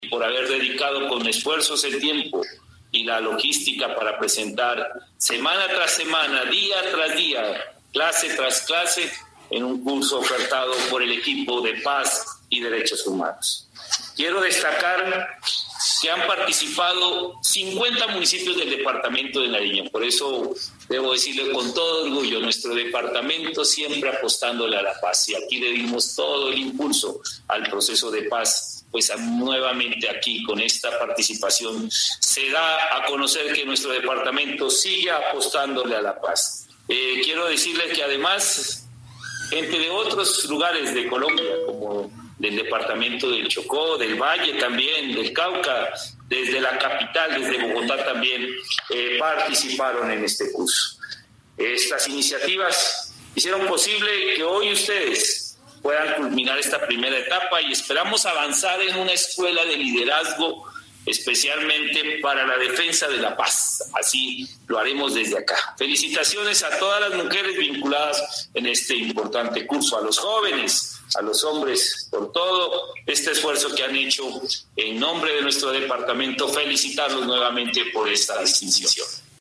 A través de una ceremonia de graduación virtual, un total de 210 hombres y mujeres, procedentes de 50 municipios de Nariño y de los departamentos de Chocó y Cundinamarca, recibieron por parte de la Gobernación de Nariño la certificación que los acredita como ‘Constructores de vida y paz en Derechos Humanos’.
Audio-Gobernador-de-Nariño-Jhon-Rojas-2.mp3